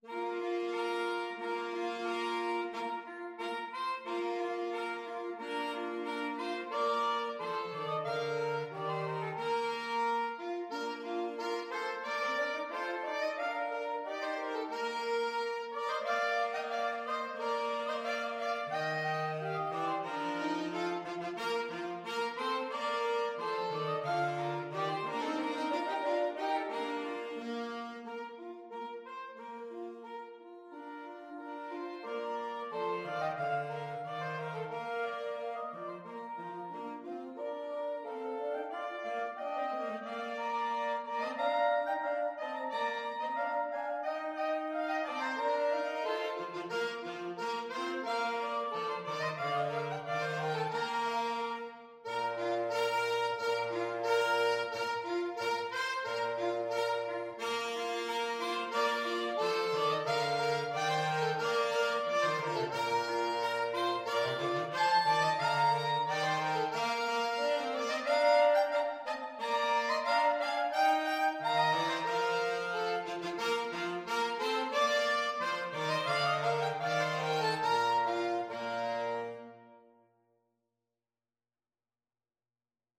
Free Sheet music for Woodwind Trio
Soprano SaxophoneAlto SaxophoneTenor Saxophone
Bb major (Sounding Pitch) (View more Bb major Music for Woodwind Trio )
March ( = c. 90)